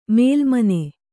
♪ mēlmane